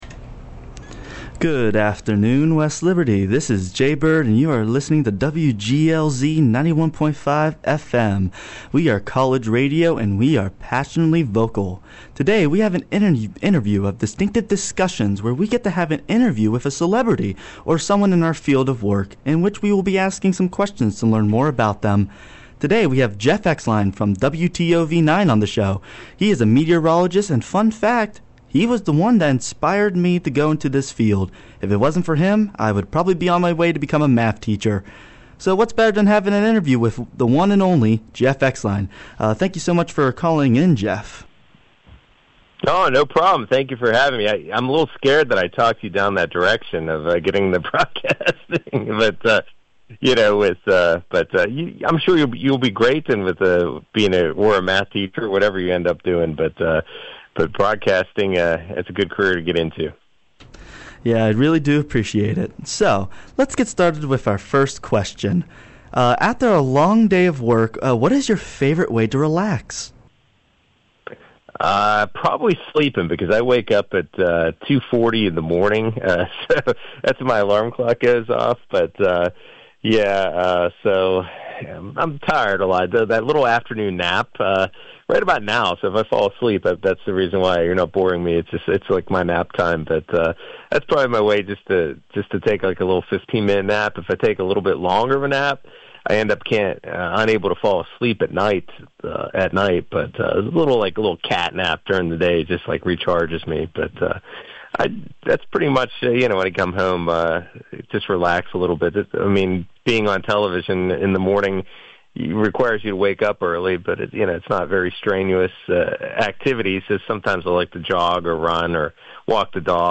We found out that meteorology has been his passion for a long time. This was truly an inspirational interview.